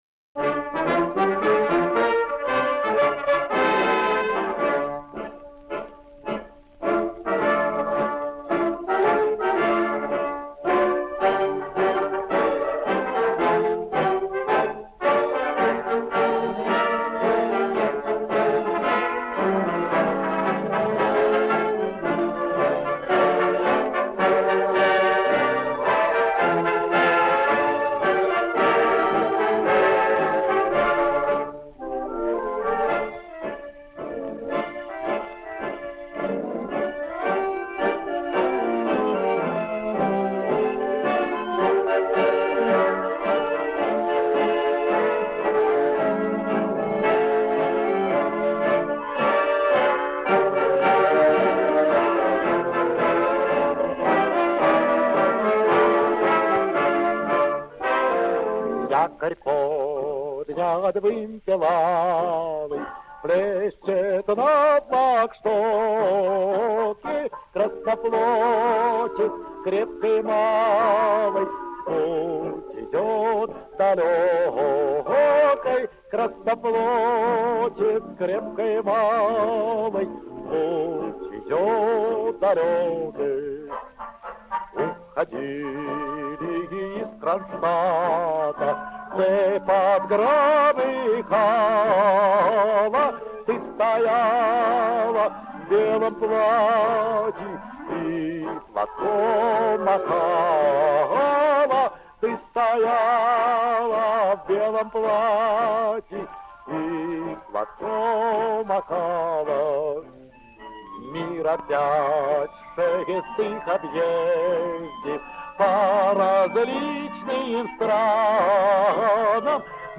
Главная / Computer & mobile / Мелодии / Патриотические песни